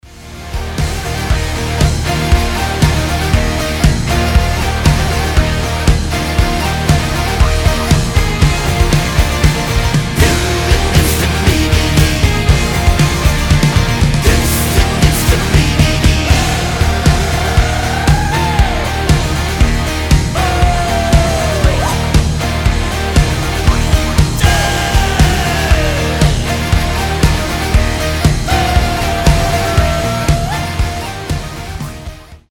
• Качество: 320, Stereo
этнические
фолк-рок
Монгольский фолк метал в мажорном исполнении